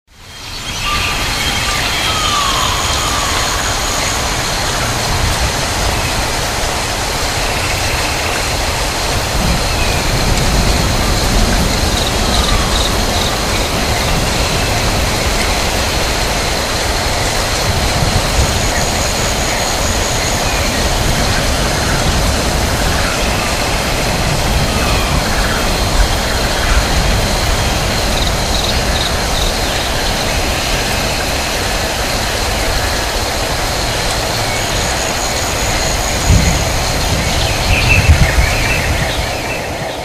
熱帯雨林特有の環境で、鳥たちが鳴いています。
ジャングル（雨と鳥のさえずり） 着信音